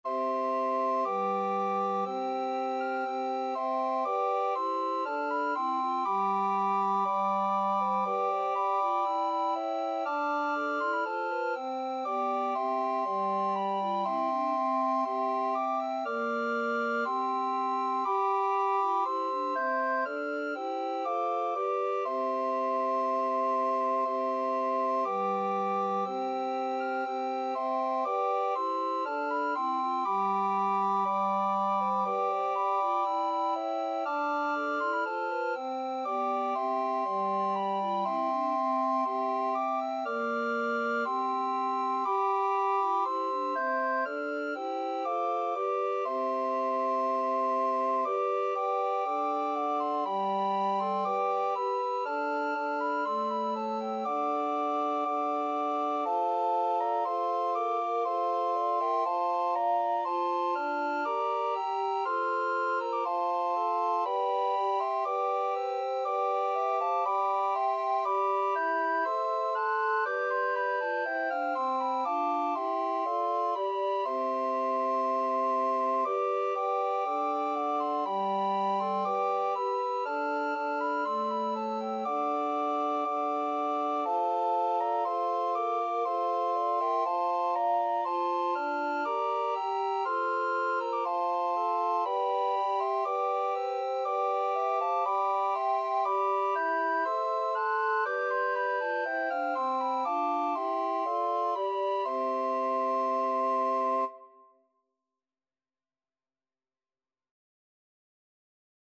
2/2 (View more 2/2 Music)
Classical (View more Classical Recorder Ensemble Music)